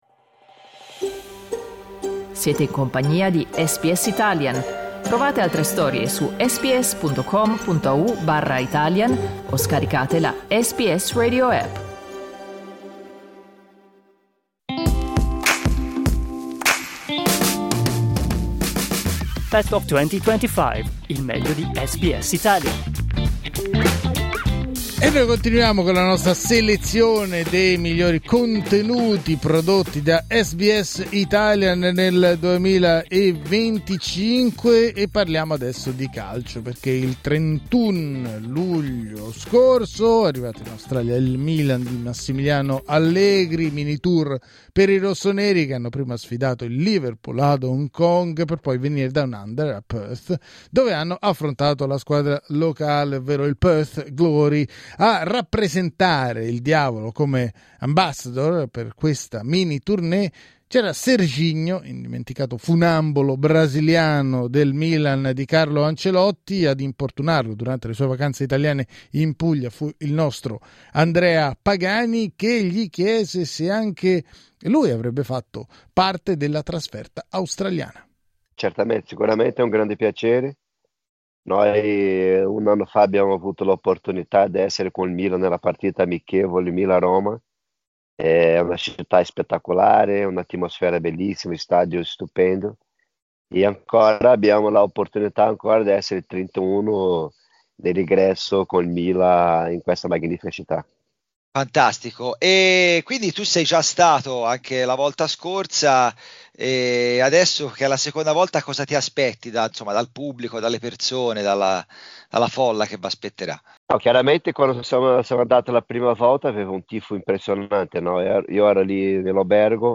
Dai nostri archivi, l'intervista all'indimenticato "Concorde" rossonero, realizzata in occasione dell'amichevole del Diavolo a Perth dello scorso luglio.